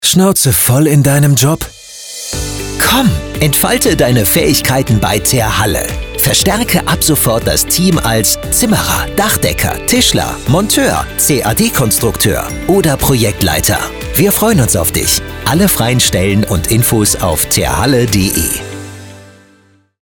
Radiospot „Terhalle als Arbeitgeber“
Radiospot-Terhalle-als-Arbeitgeber.mp3